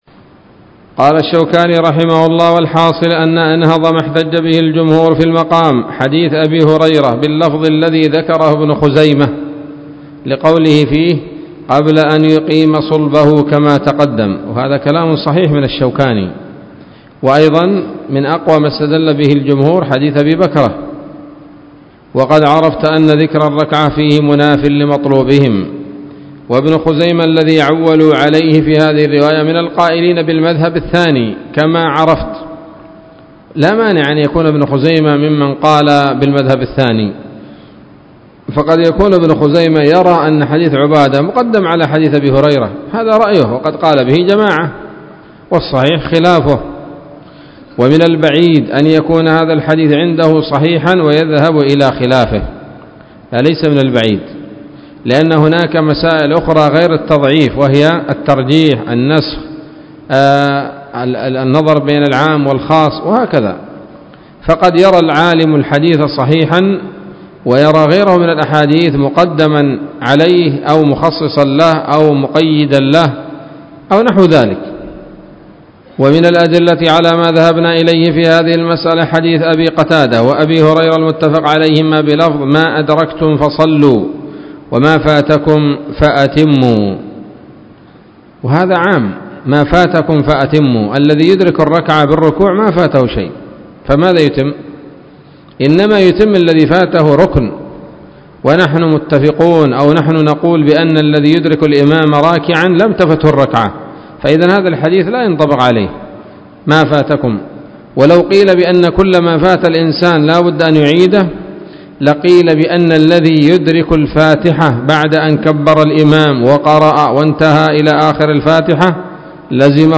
الدرس السادس والثلاثون من أبواب صفة الصلاة من نيل الأوطار